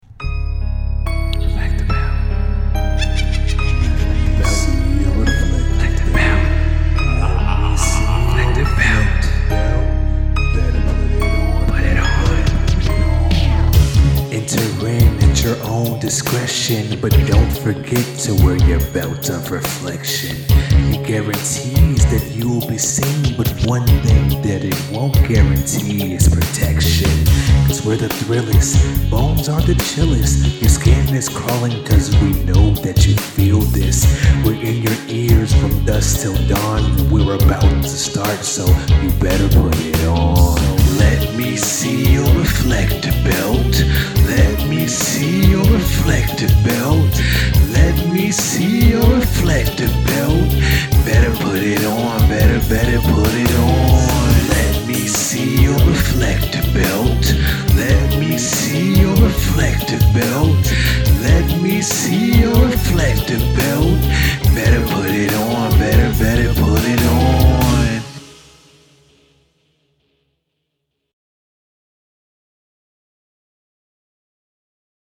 Original rap created for the Halloween Edition of the Reflective Belt Podcast